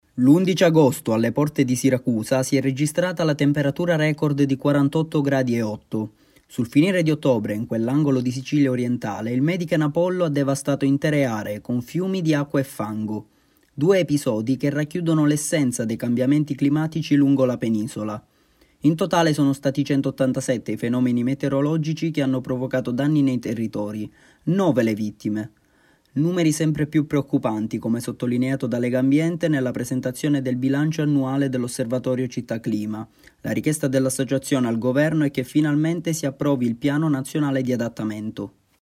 Servizio-Legambiente-30-dicembre.mp3